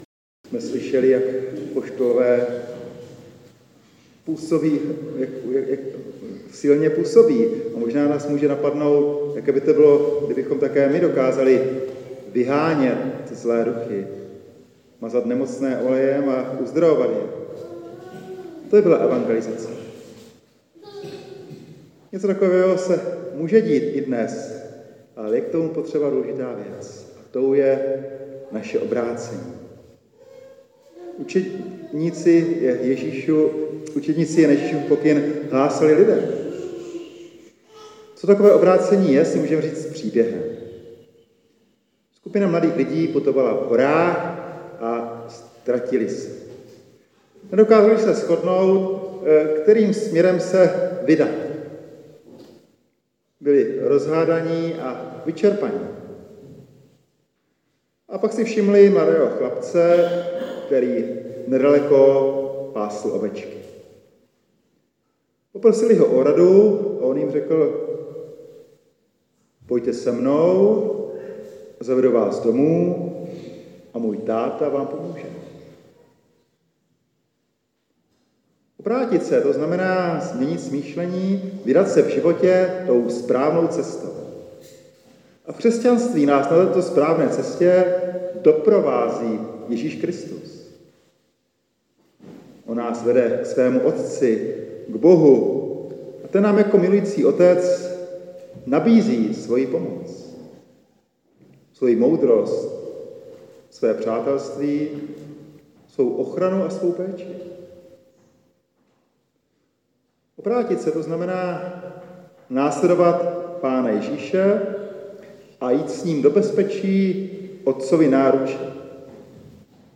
Kázání z 15. neděle v mezidobí z kostela ve Vranově nad Dyjí dne 14.7.2024.